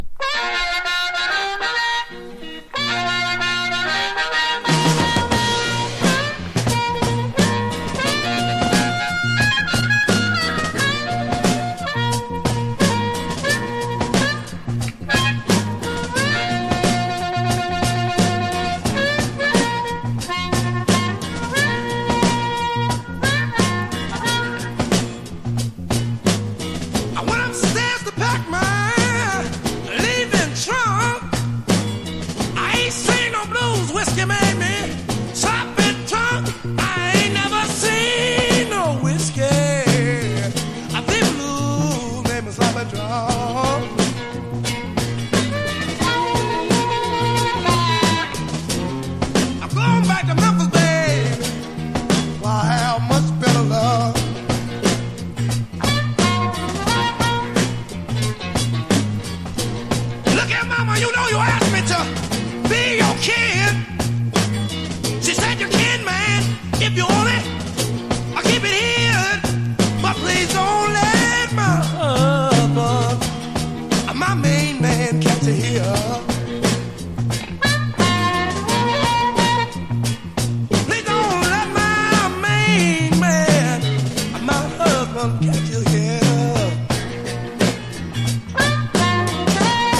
全体的に楽曲はアップテンポなエレクトリックなカントリー・ブルース。
FOLK# 60’s ROCK# 70’s ROCK# BLUES ROCK / SWAMP